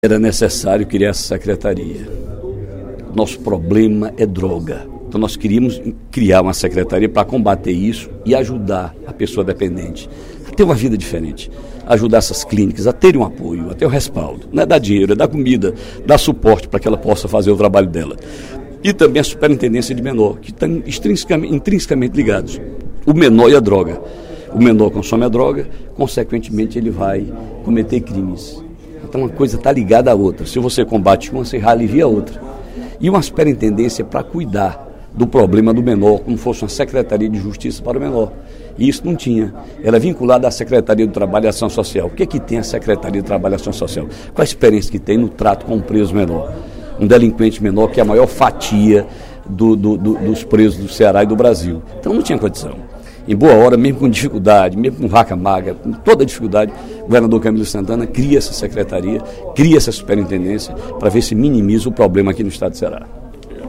O deputado Ferreira Aragão (PDT) elogiou, durante o primeiro expediente da sessão plenária desta sexta-feira (10/06), iniciativas do Governo do Estado como a Secretaria Especial de Políticas sobre Drogas e a Superintendência do Sistema Estadual de Atendimento Socioeducativo, aprovada por meio de mensagem votada na Assembleia na quinta-feira (09/06).